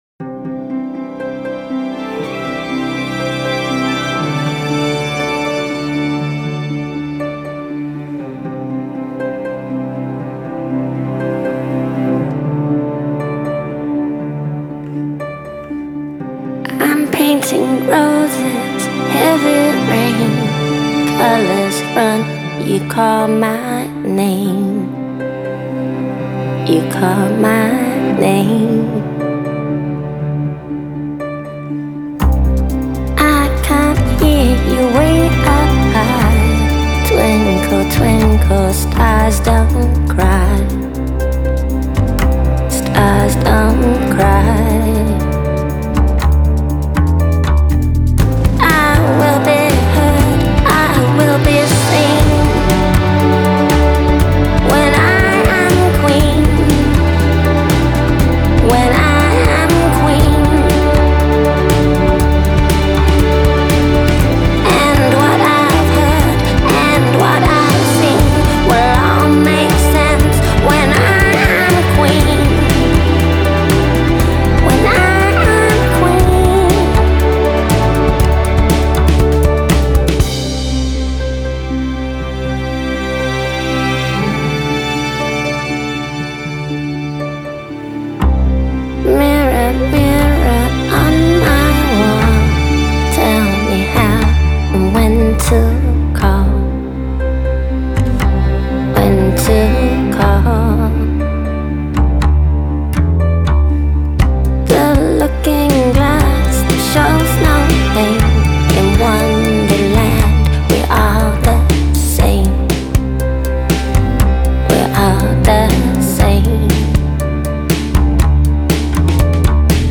Genre: Electronic, Alternative, Downtempo